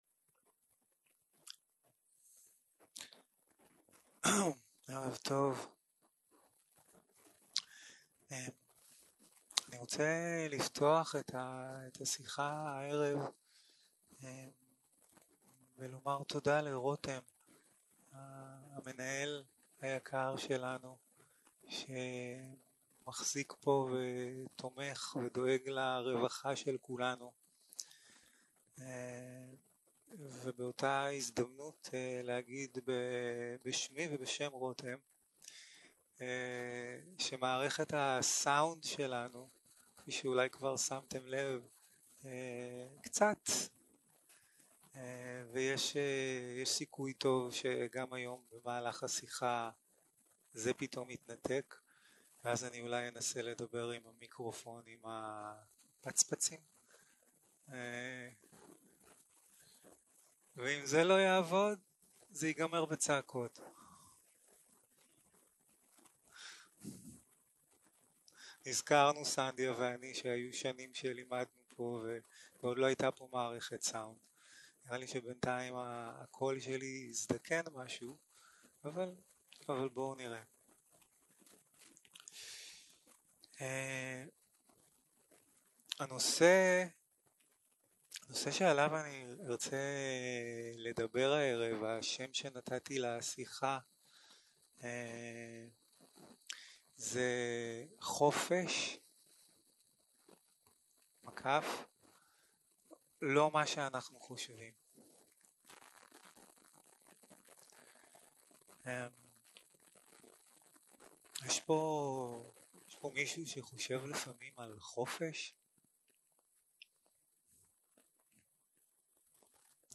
סוג ההקלטה: שיחות דהרמה